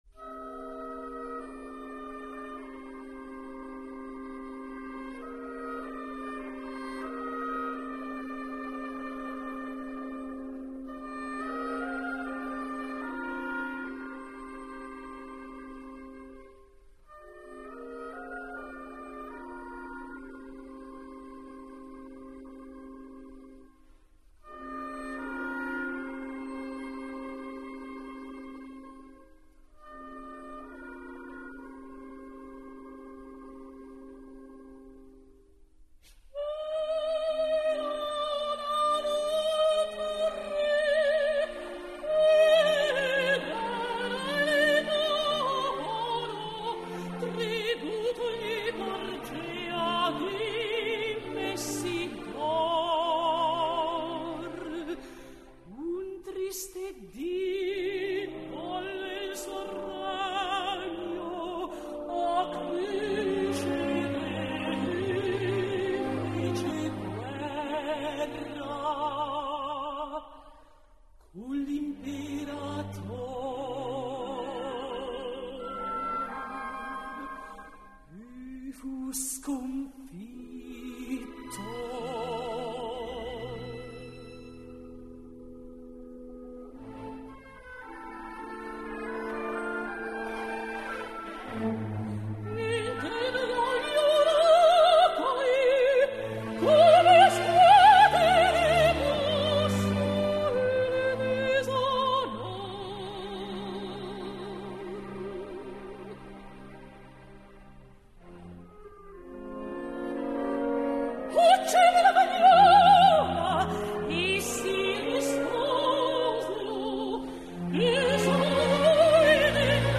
Luisa [Sopran]